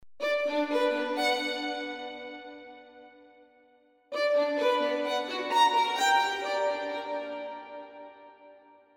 Meinen letzten Klingelton habe ich aus dem Intro von nem orchestralen Song rausgeschnitten, den ich irgendwann 2005 mal gemacht hatte...